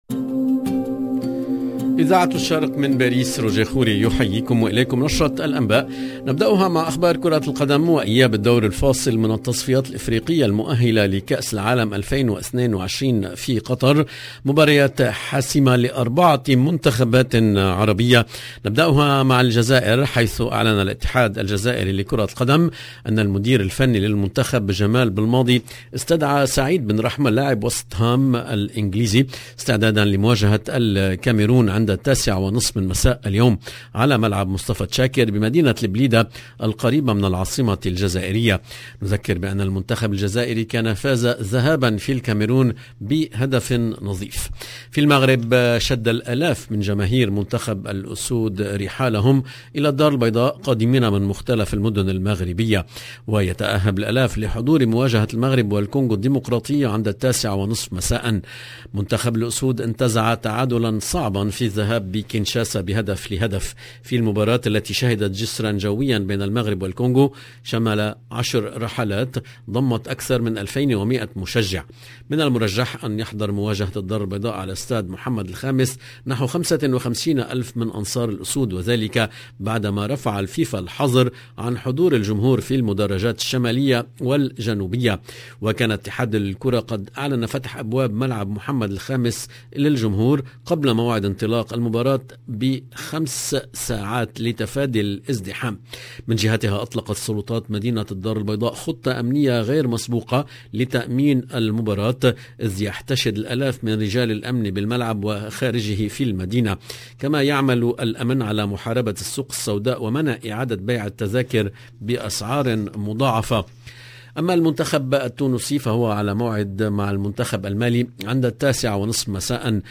LE JOURNAL DU SOIR EN LANGUE ARABE DU 29/03/22